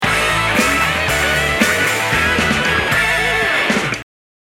derdiedas-custom-jingle.mp3